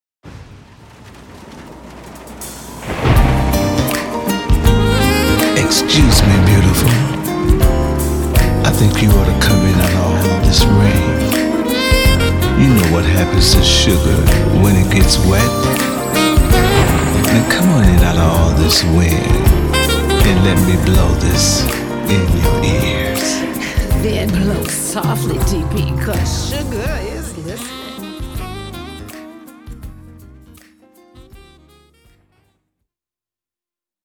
Saxophonist